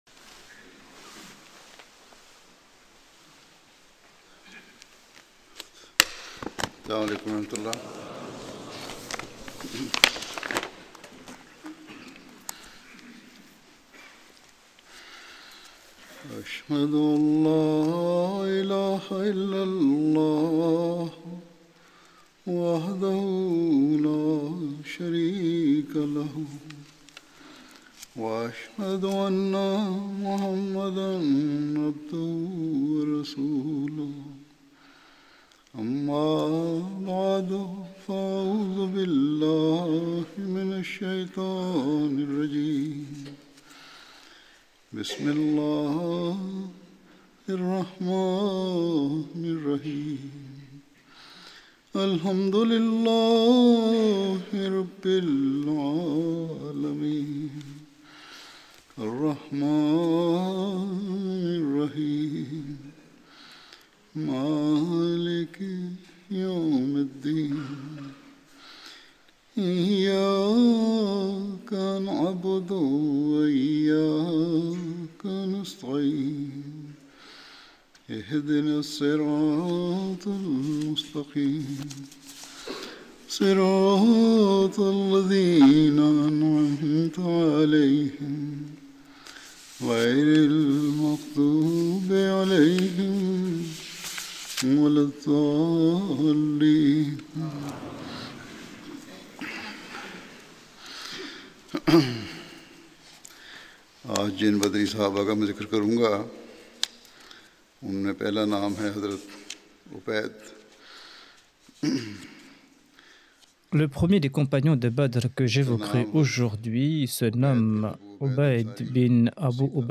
Sermon du : 03.05.2019 prononcé par Sa Sainteté le Calife, Hadrat Mirza Masroor Ahmad